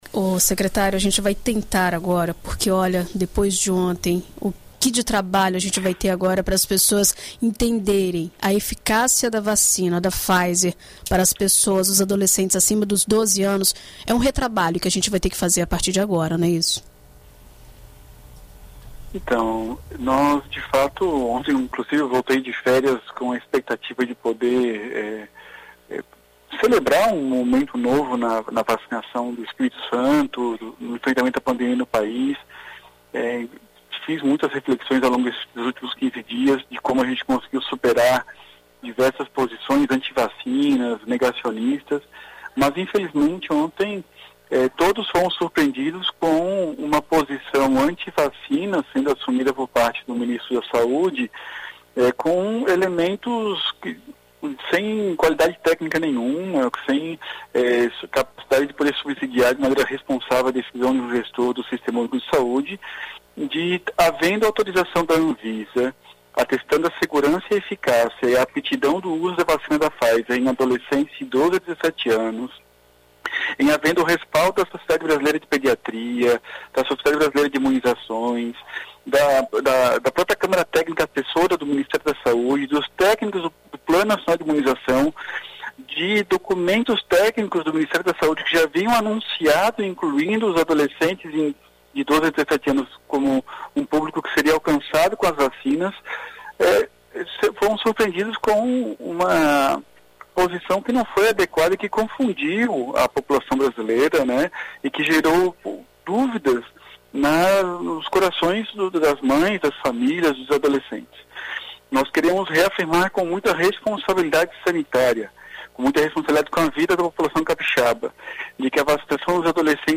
Em entrevista à BandNews FM Espírito Santo nesta sexta-feira (17), Nésio esclarece a decisão tomada pela Secretaria de Saúde de dar continuidade ao esquema atual e fala sobre os pareceres técnicos que embasaram a decisão.